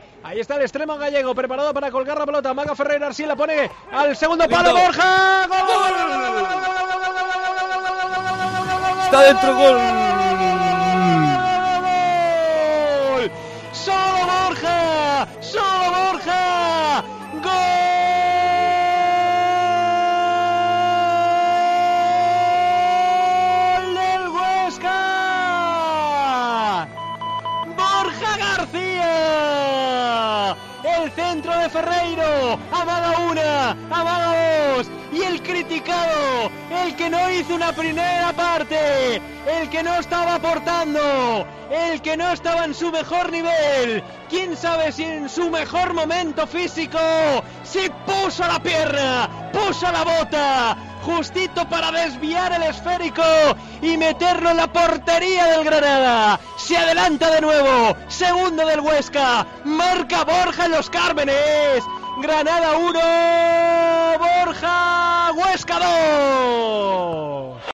Narración Gol de Borja García / Granada 1-2 Huesca